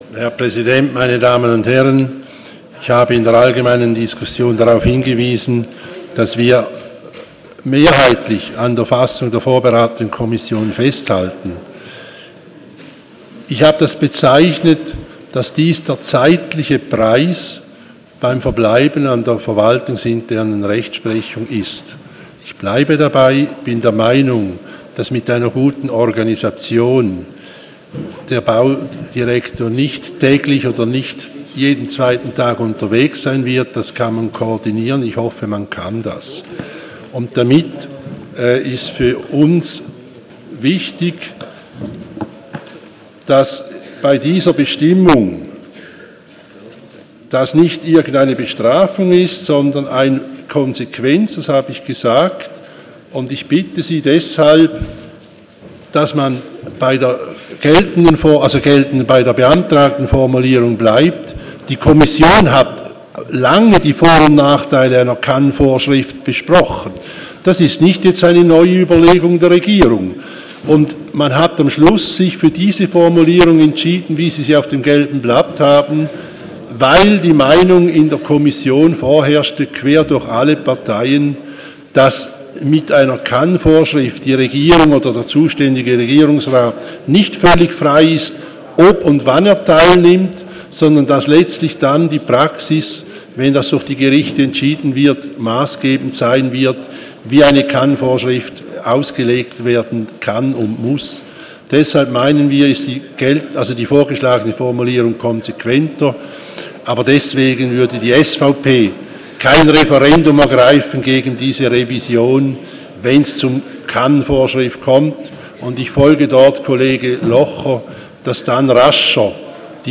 20.9.2016Wortmeldung
Session des Kantonsrates vom 19. und 20. September 2016